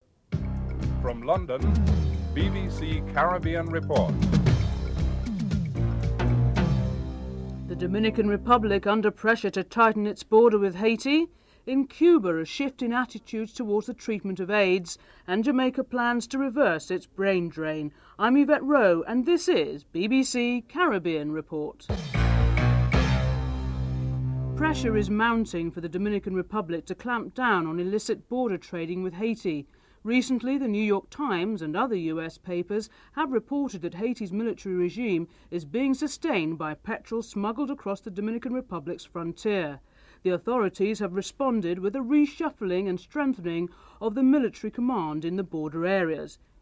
1. Headlines (00:00-00:25)
9. Theme music (14:43-15:07)